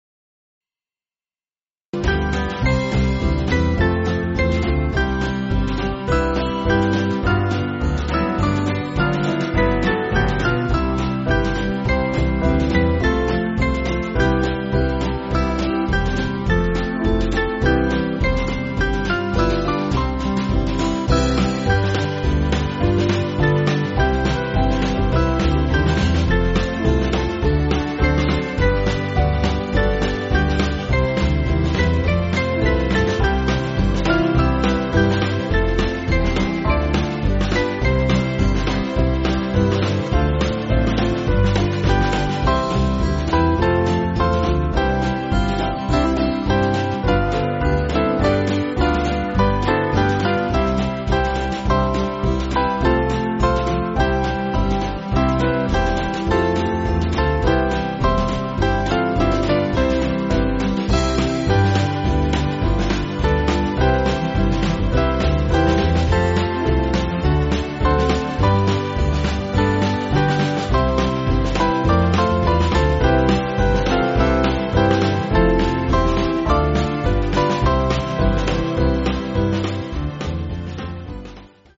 Small Band
(CM)   4/C-Db